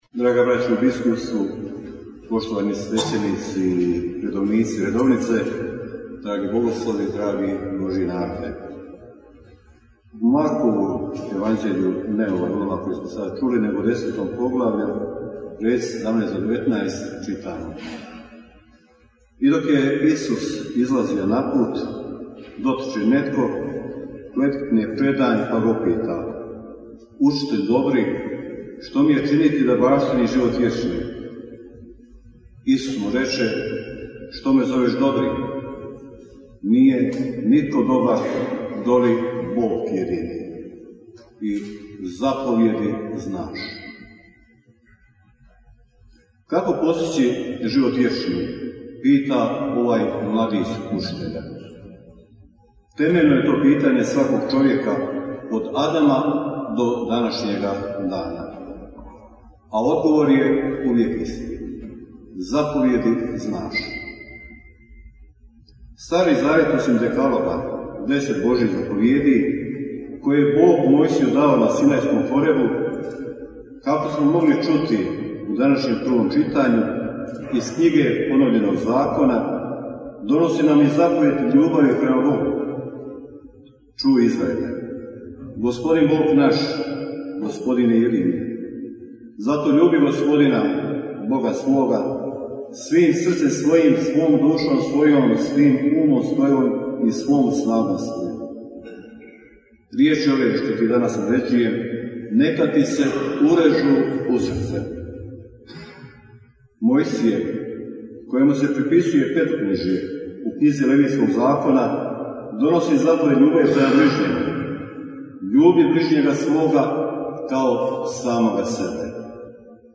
Audio: Propovijed biskupa Majića u sarajevskoj katedrali uoči 91. redovitog zasjedanja Biskupske konferencije BiH - BANJOLUČKA BISKUPIJA
Biskupi Biskupske konferencije Bosne i Hercegovine i biskupi delegati, 3. studenog 2024. slavili su euharistiju u katedrali Srca Isusova u Sarajevu uoči 91. redovitog zasjedanja Biskupske konferencije BiH.